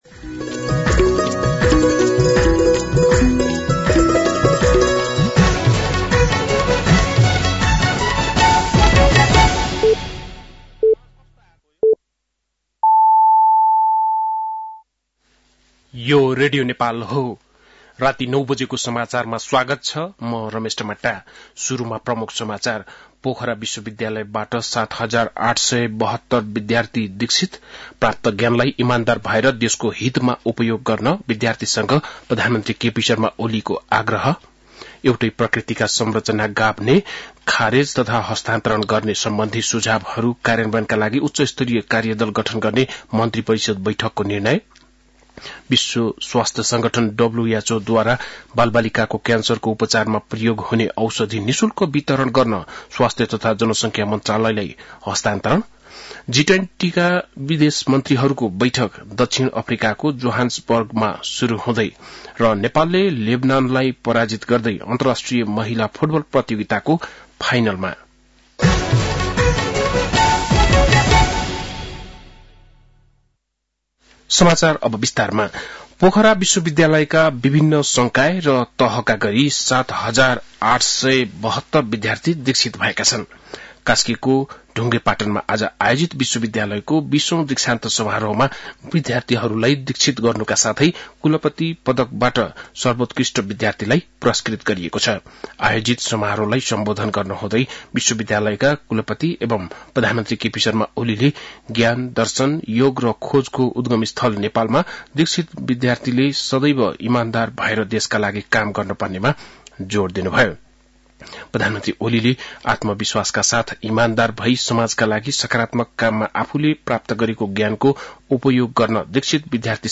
बेलुकी ९ बजेको नेपाली समाचार : ९ फागुन , २०८१
9-PM-Nepali-NEWS-.mp3